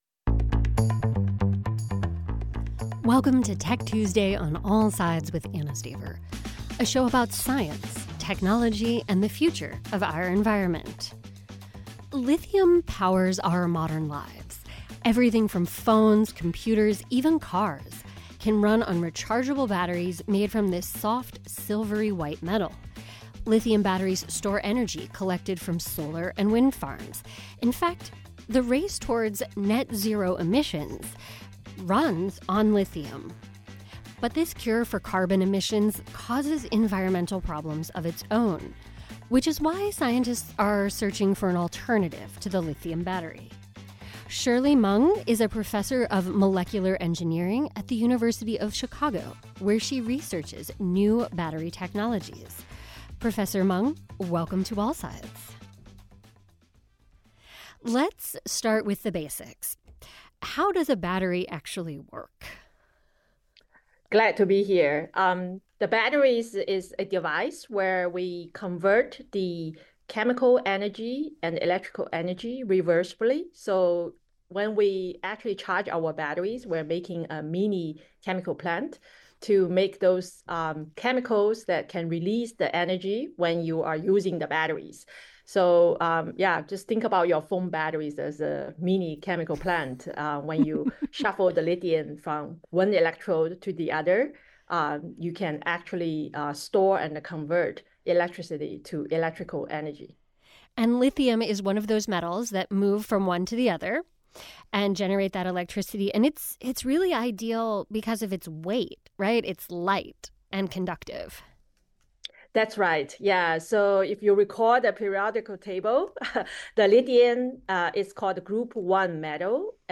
WOSU Public Media’s daily news program that dives deep into issues important to Central Ohio.